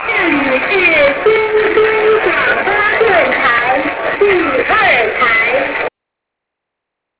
Each channel identifies itself at the beginning of the broadcasts as "the third" or "the fourth" program.